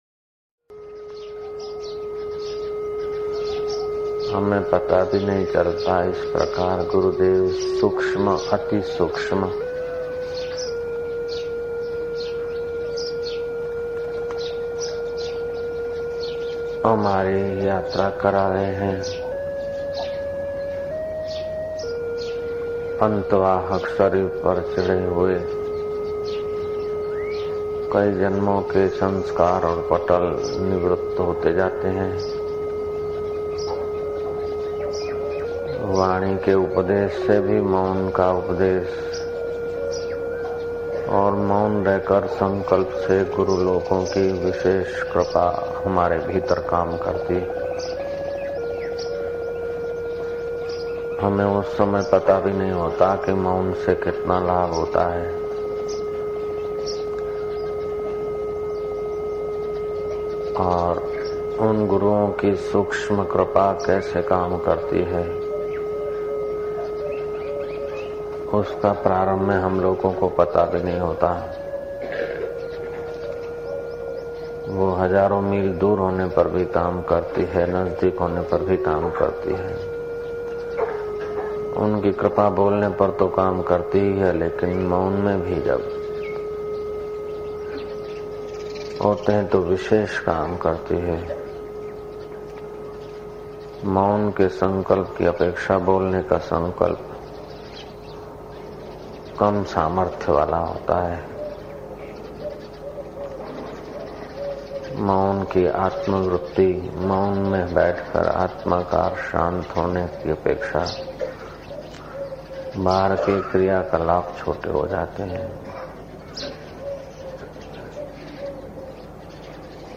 Audio Satsang MP3s of Param Pujya Sant Shri Asharamji Bapu Ashram